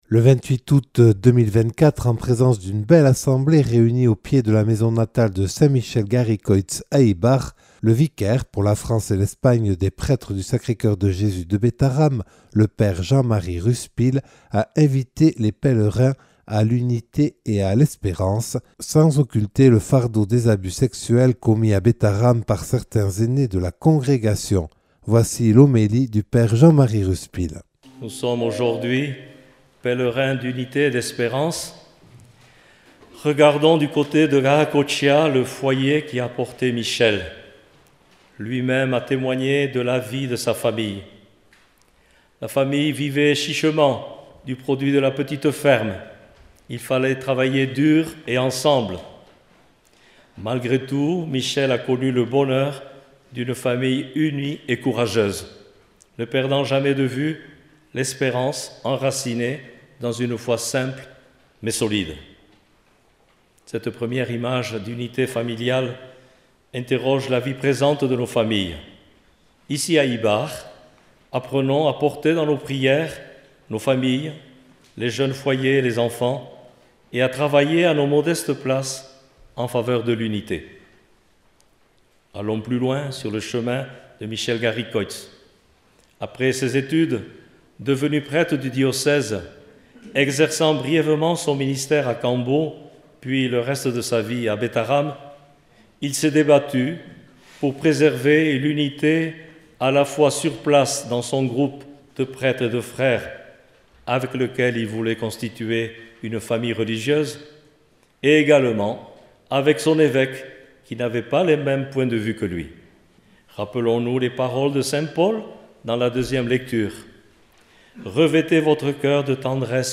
Voici son homélie.